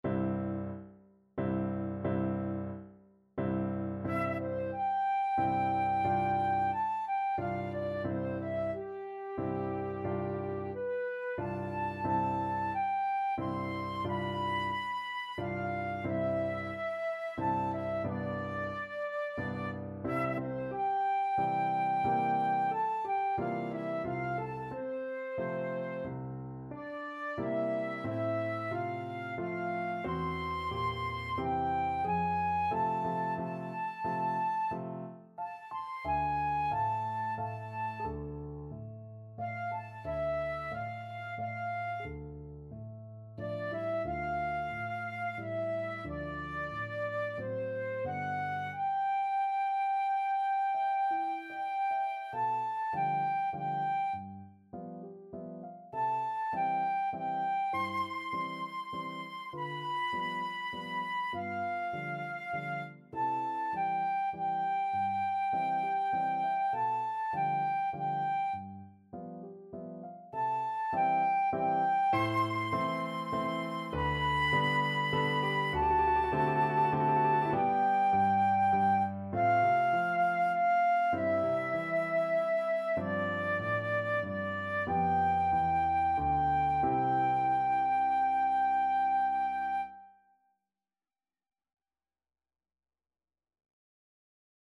Flute
3/4 (View more 3/4 Music)
C major (Sounding Pitch) (View more C major Music for Flute )
~ = 90 Allegretto moderato